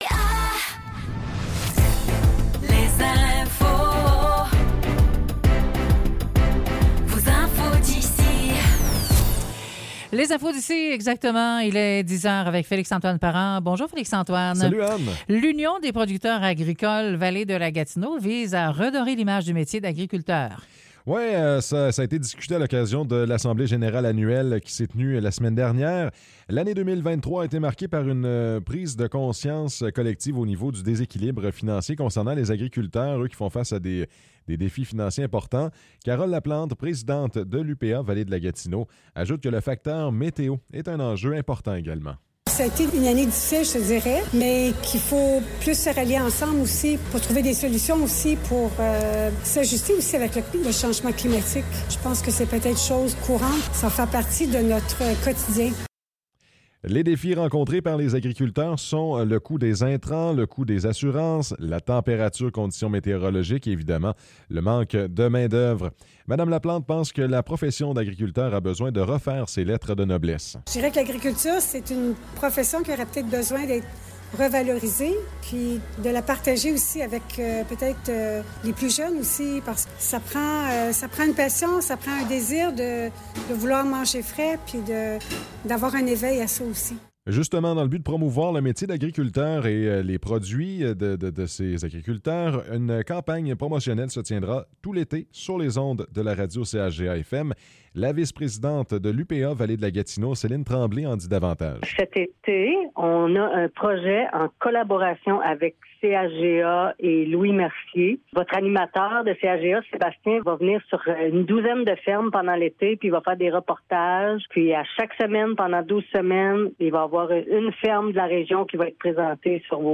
Nouvelles locales - 26 mars 2024 - 10 h